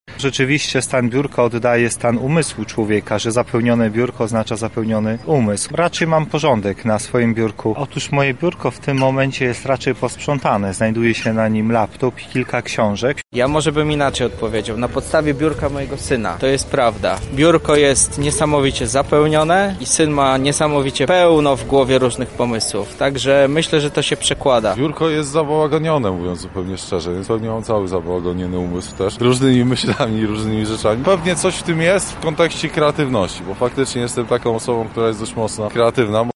[SONDA] Dzień Sprzątania Biurka – jak wyglądają biurka lublinian?
Dlatego zapytaliśmy mieszkańców Lublina, jak oni określają siebie na podstawie stanu swojego miejsca pracy.